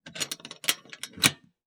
Part_Assembly_46.wav